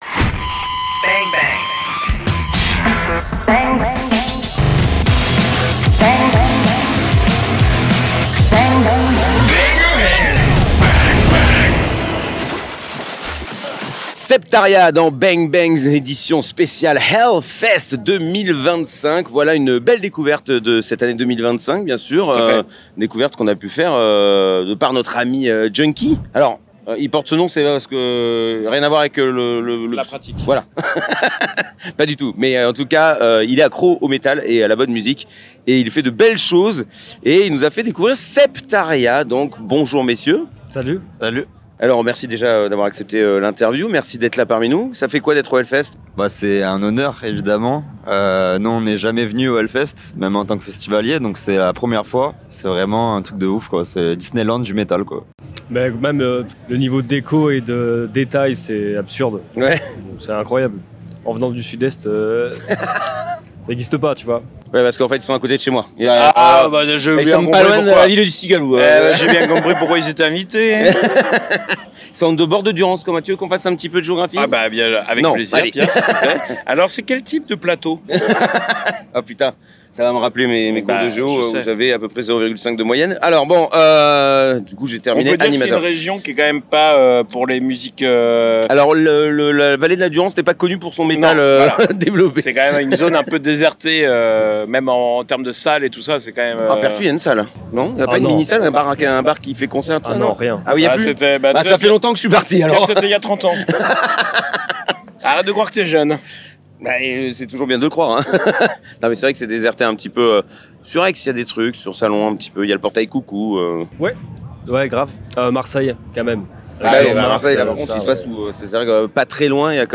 Voilà encore une édition de HELLFEST accomplie ! Et nous avons fait 23 interviews !